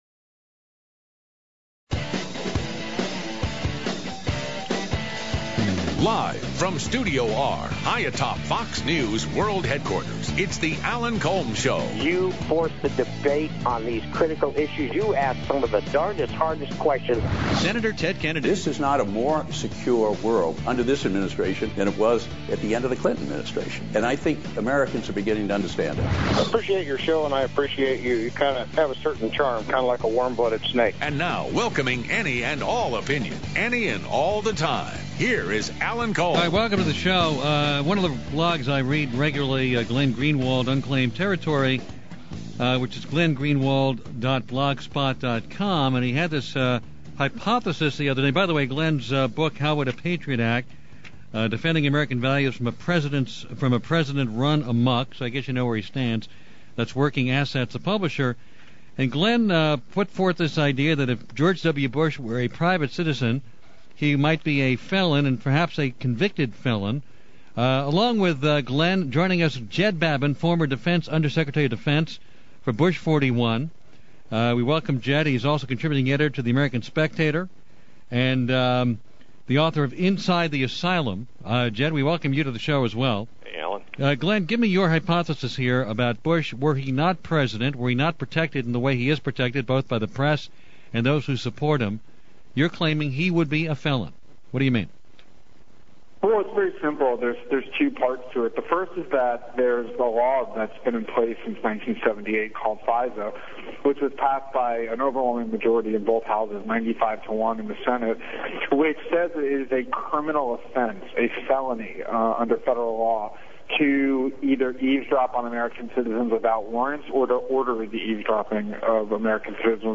Gleen Greenwald was on the Alan Colmes show yesterday and got into a rather hefty debate with former deputy undersecretary of defense (under Bush 41), Jed Babbin
Babbin gets really nasty and says on the air that Glenn has "no god damn idea what he's talking about". Babbin tries to say that FISA "does not cover foreign intelligence gathering", which is funny since it is called the Foreign Intelligence Surveillance Act. Of course Babbin tries a typical neo-con tactic of yelling and screaming over someone when they are talking, but it fails.